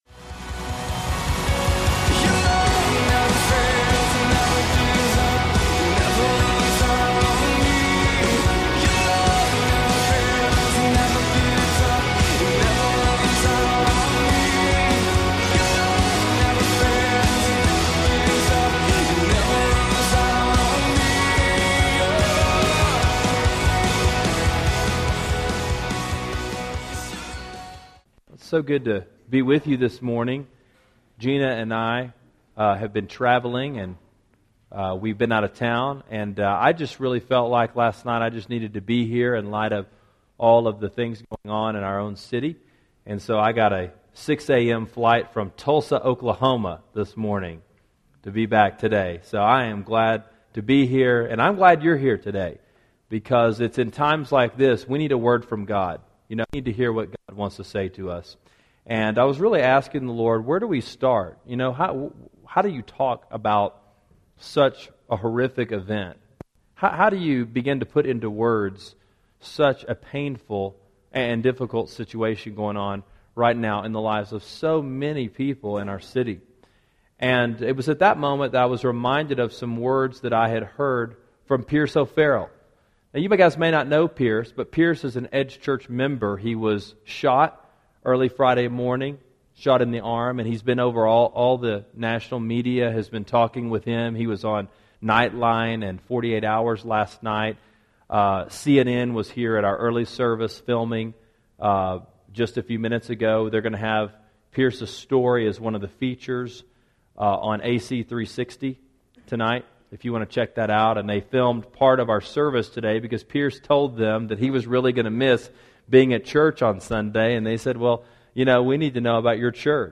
The Power Of Forgiveness – Matthew 18:21-35 – Sermon Sidekick